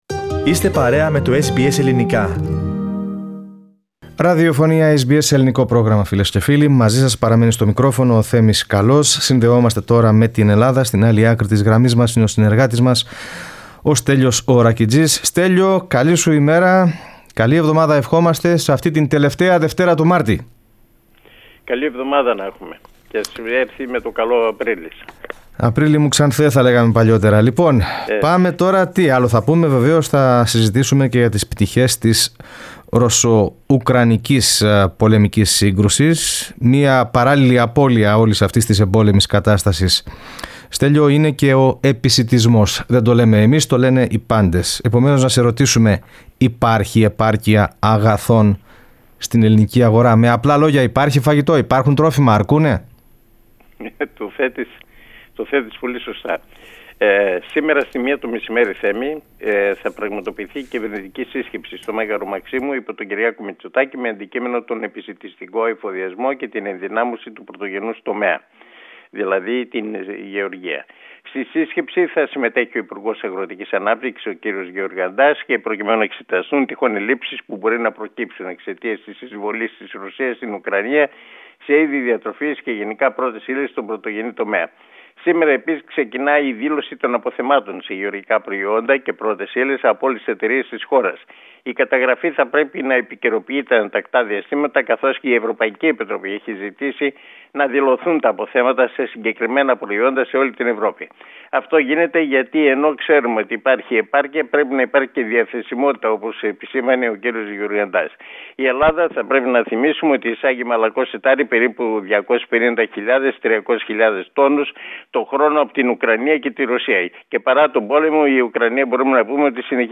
H εβδομαδιαία ανταπόκριση από την Αθήνα.